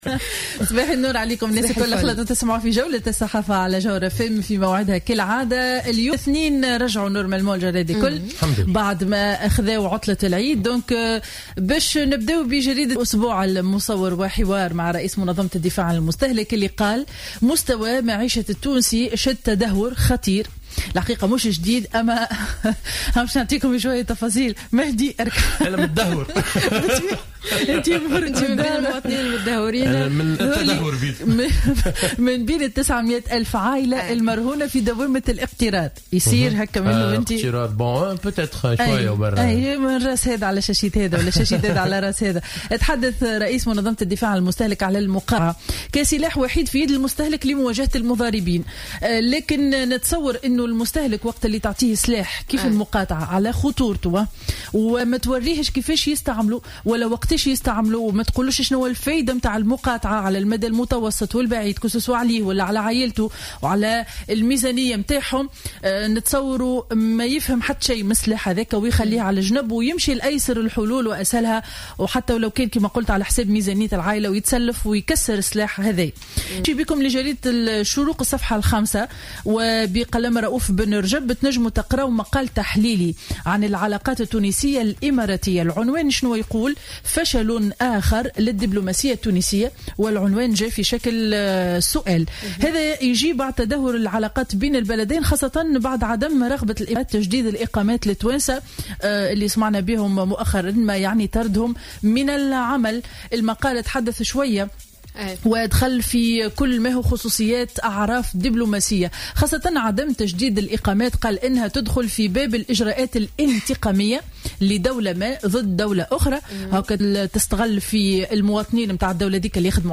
Revue de presse du lundi 28 septembre 2015